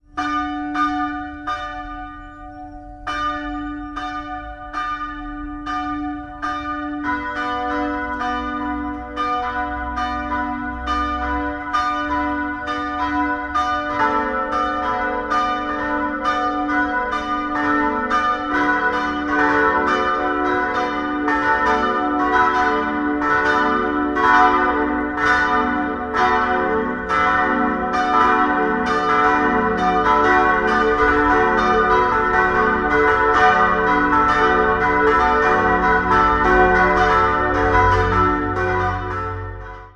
Idealquartett: fis'-a'-h'-d'' Die Glocken stammen aus der Gießerei Bachert in Karlsruhe aus dem Jahr 1958, wobei die kleinste Glocke in Dur-Rippe gegossen wurde.